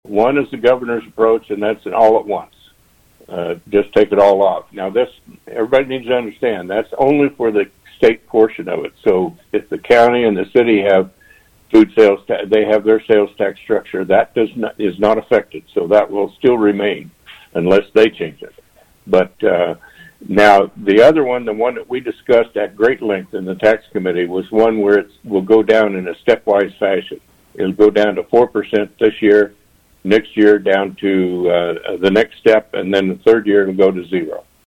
51st District Representative Ron Highland, a guest on KVOE’s Morning Show this week, says part of the conversation will include which food categories are eligible for reducing or eliminating the tax. Another component involves exactly when to eliminate the tax.